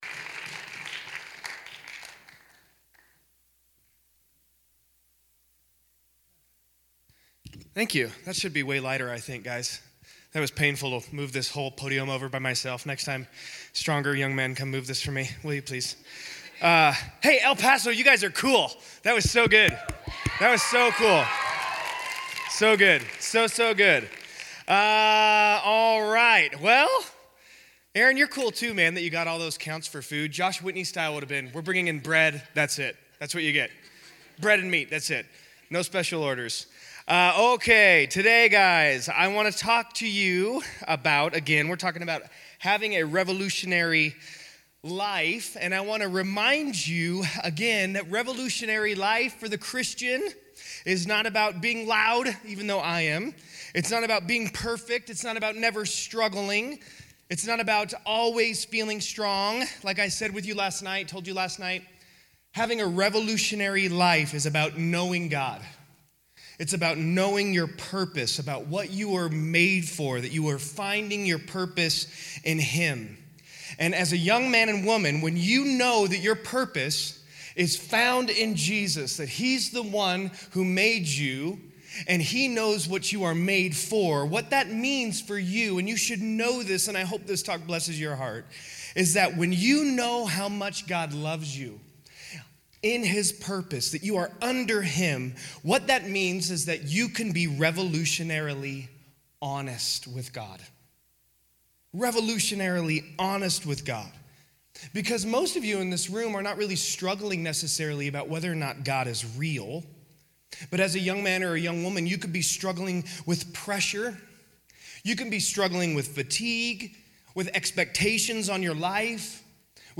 Winter Teen Conference - Teaching 2 - Summitview Church